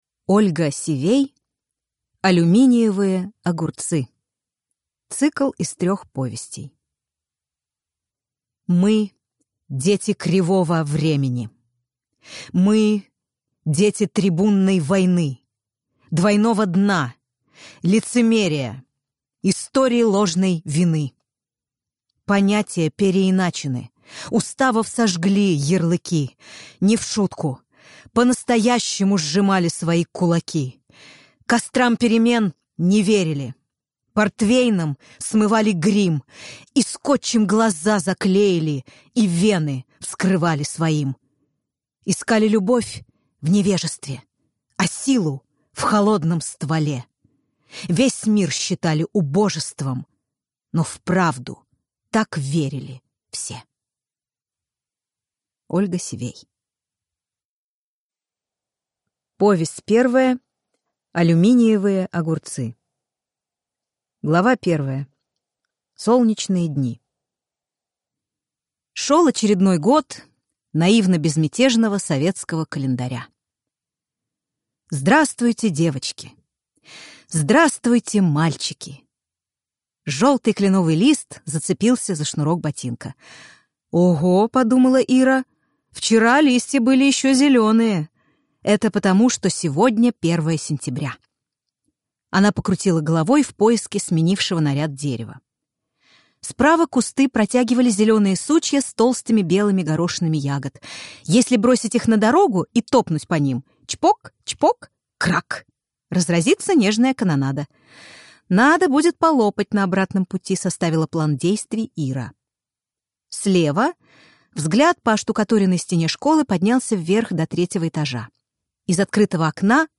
Аудиокнига Алюминиевые огурцы | Библиотека аудиокниг